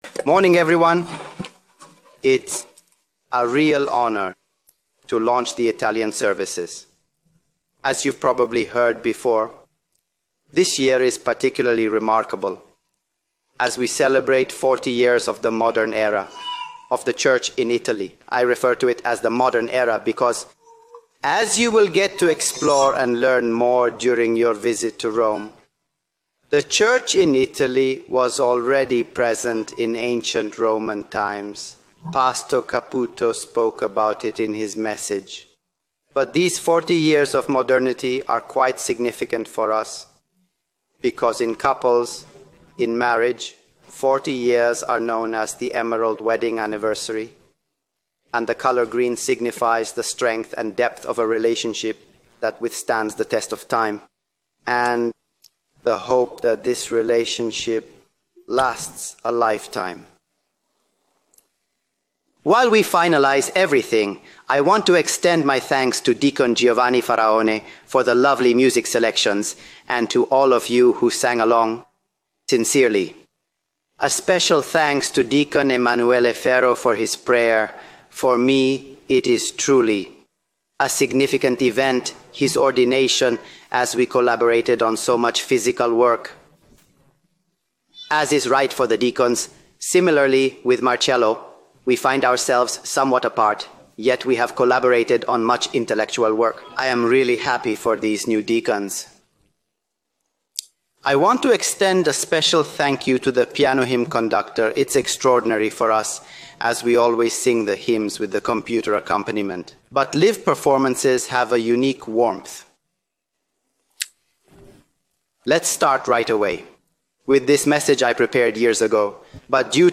Given in Sabaudia, Italy
(Presentation in Italian and English is provided) Dubbed into English with AI Studying the bible?